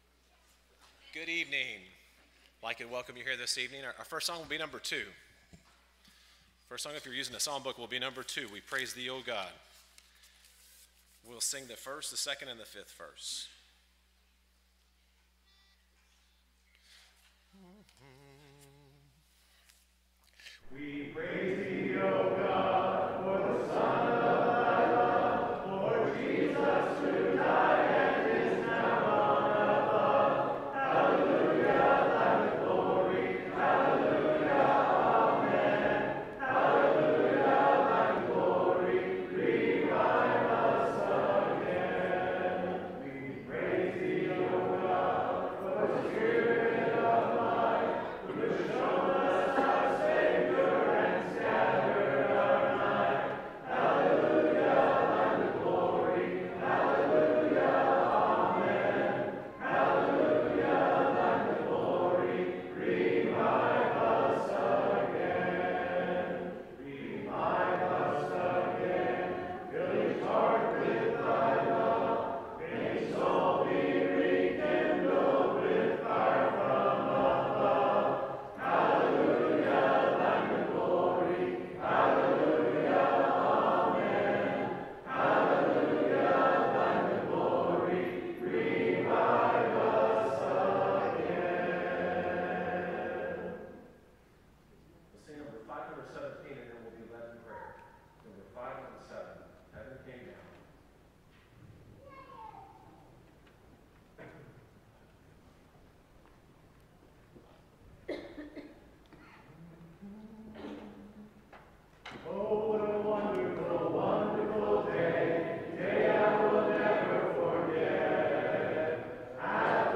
Matthew 28:19-20, English Standard Version Series: Sunday PM Service